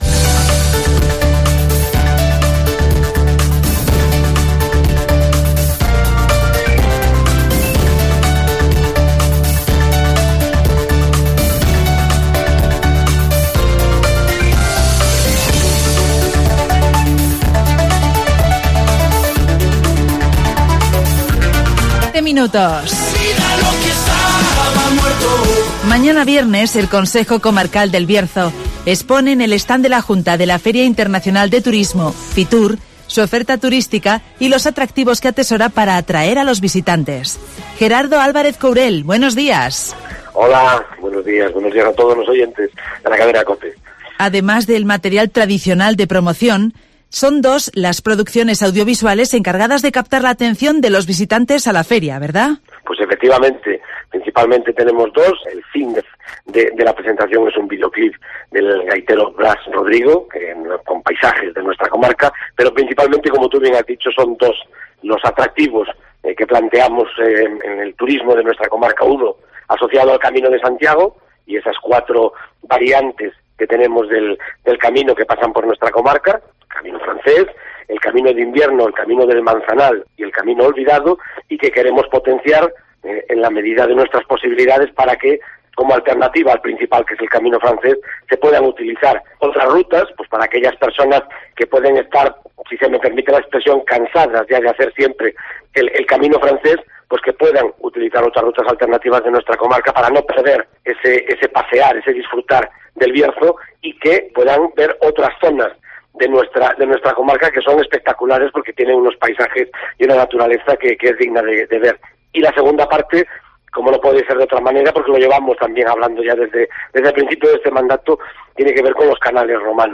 El Camino de Santiago y los canales romanos de Las Médulas serán las propuestas del Bierzo en Fitur (Entrevista a Gerardo Álvarez Courel)